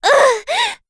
Dosarta-Vox_Damage_03.wav